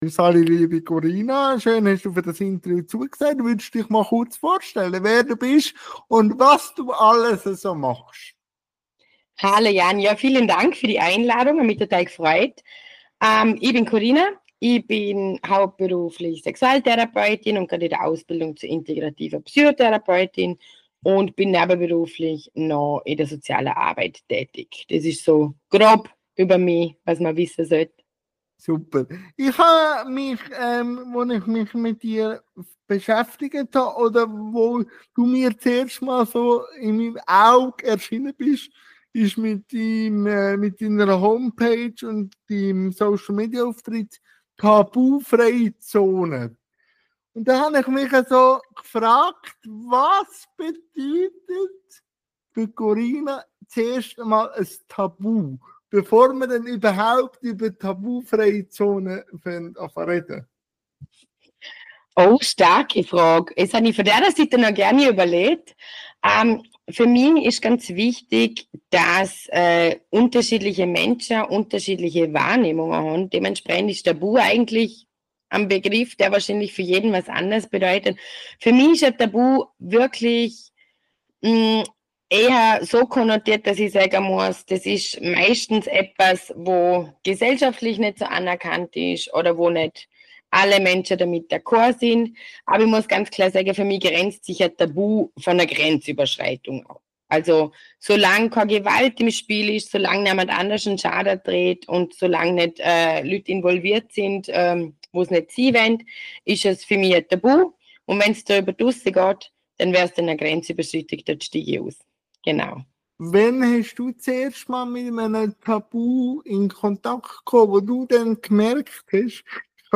Ich danke meinem Gast und wünsche viel Spass beim Interview